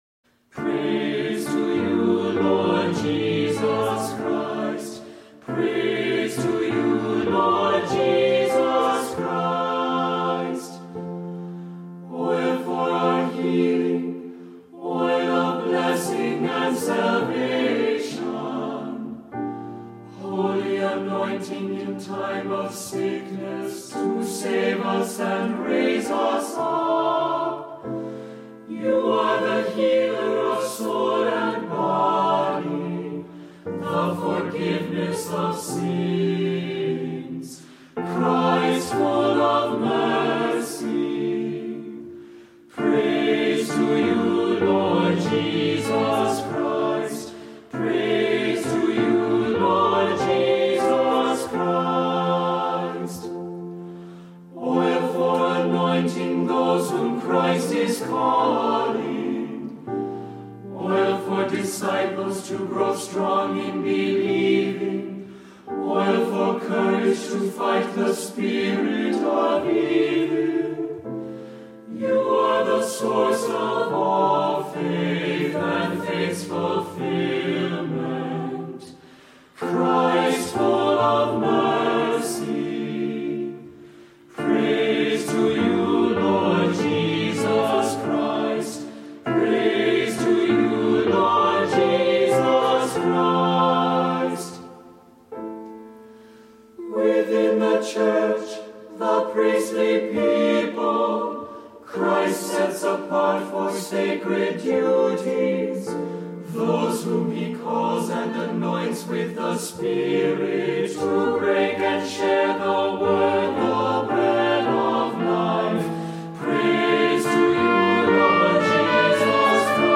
Voicing: SATB; Assembly; Cantor